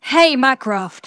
synthetic-wakewords
ovos-tts-plugin-deepponies_Applejack_en.wav